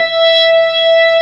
55o-org18-E5.aif